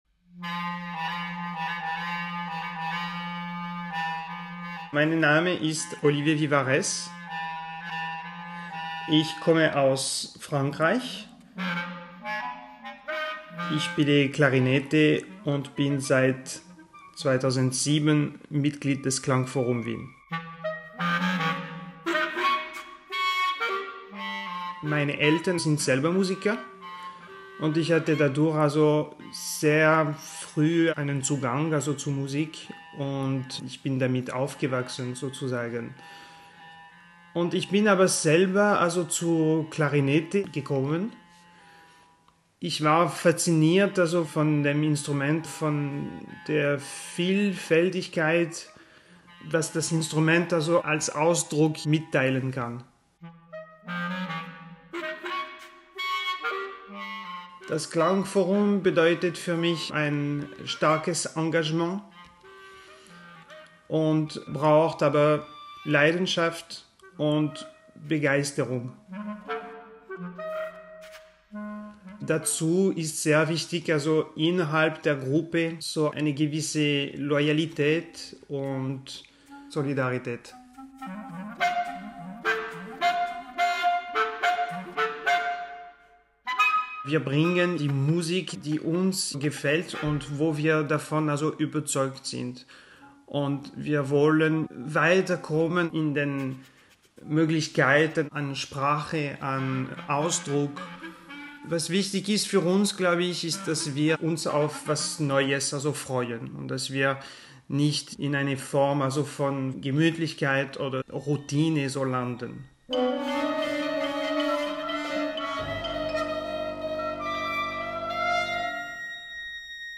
Ö1 Audio-Porträt https